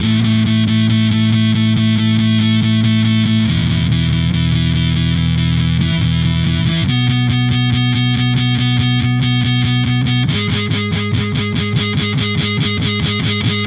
十月吉他
Tag: 失真 低音吉他 循环 音调